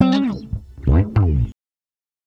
Bass Lick 34-04.wav